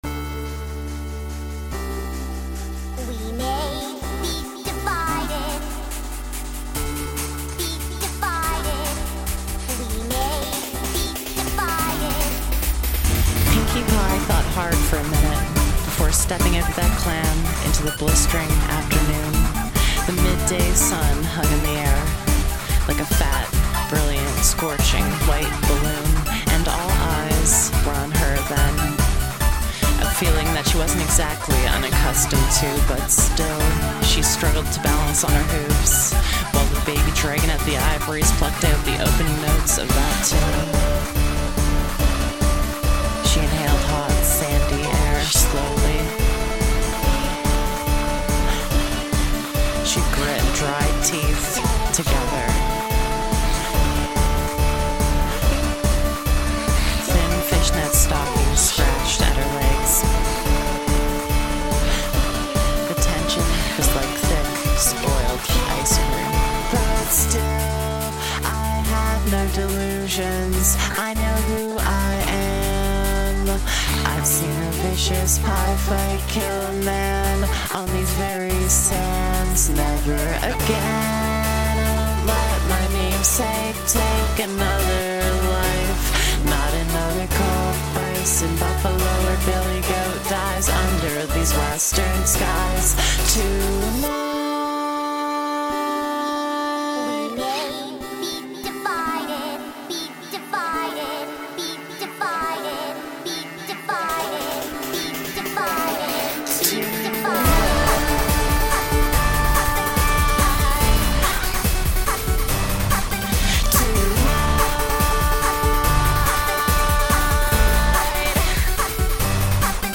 chipbit rendition